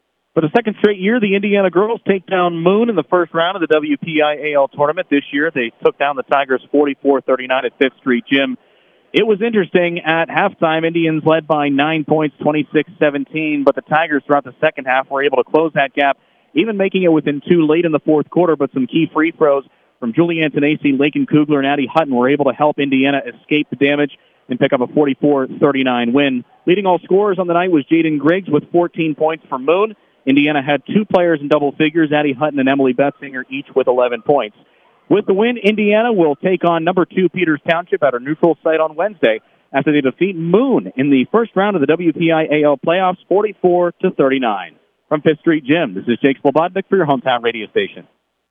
indiana-v-moon-girls-recap-2-14.mp3